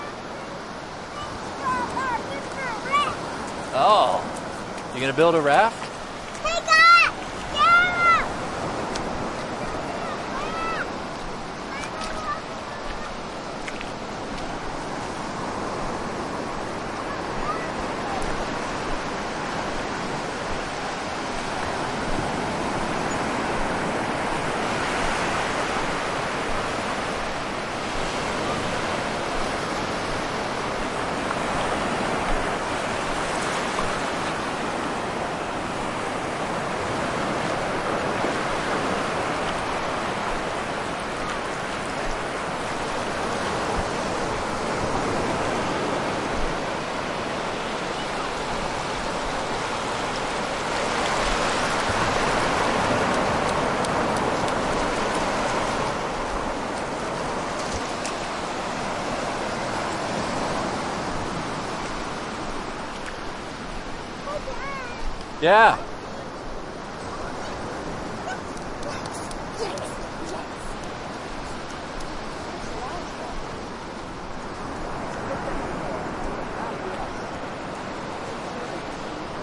描述：在玻利瓦尔半岛的波浪
Tag: 沙滩 海岸 海浪 海岸 海岸 海洋 海滨 现场记录 冲浪